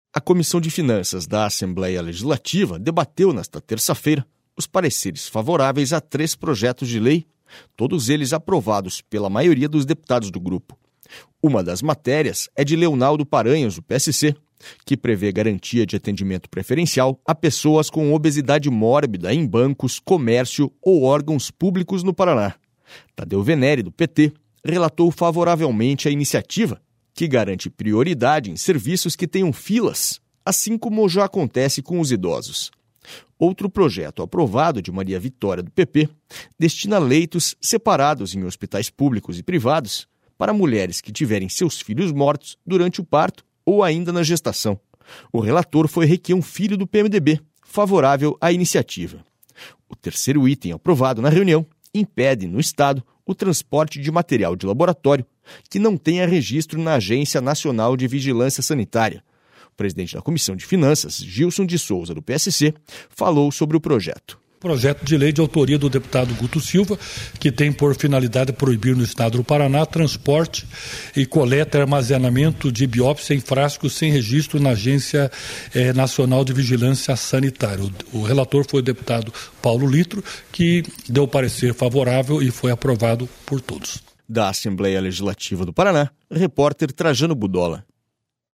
SONORA GILSON DE SOUZA